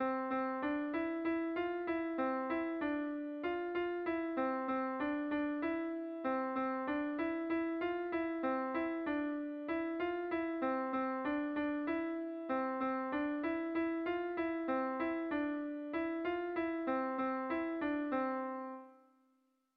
Erlijiozkoa
AAA2